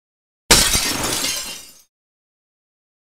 جلوه های صوتی
دانلود صدای شکستن لیوان و شیشه 2 از ساعد نیوز با لینک مستقیم و کیفیت بالا